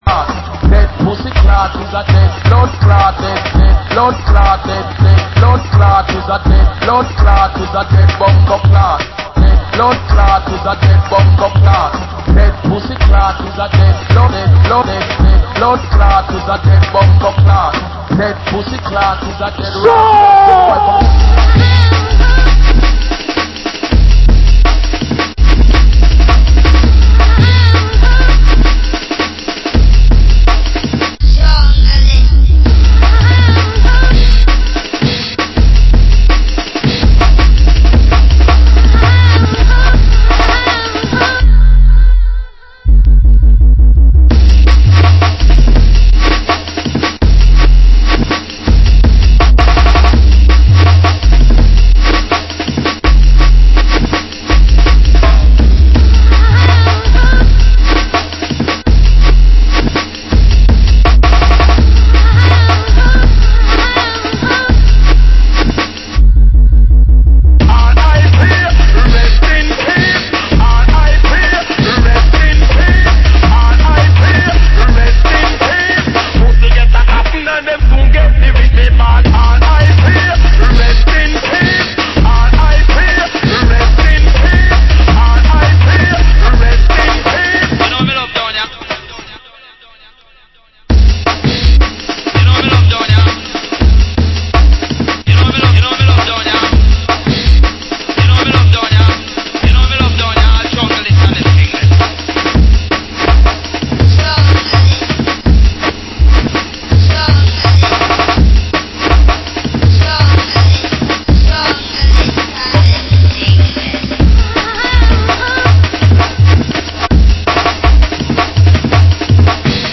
Genre Jungle